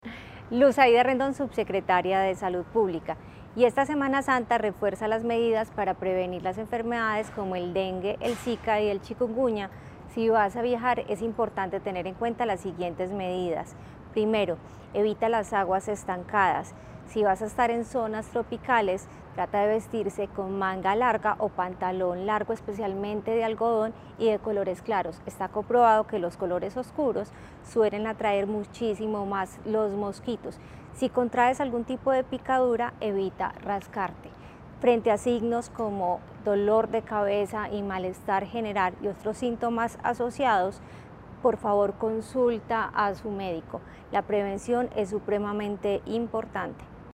Declaraciones-subsecretaria-de-Salud-Publica-Luz-Aida-Rendon.mp3